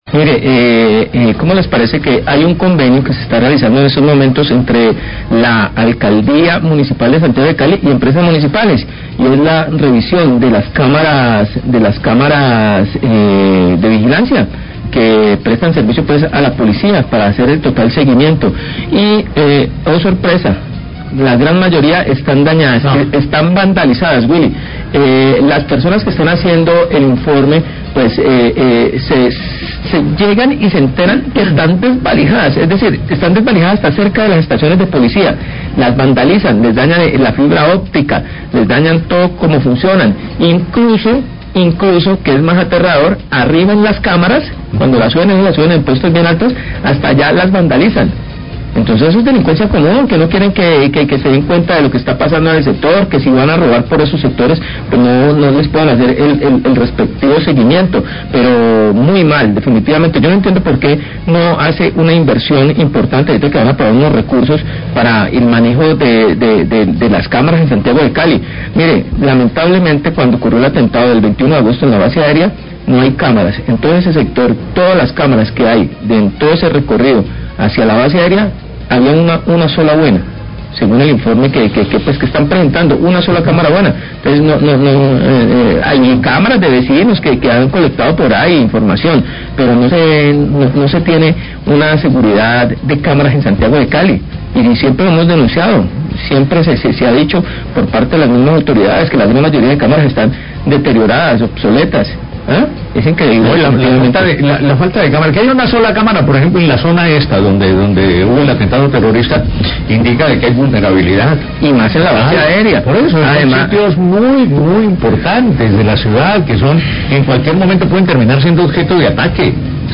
Radio
Periodistas denuncian que la gran mayoría de las cámaras están dañadas, desvlijadas o vandalizadas.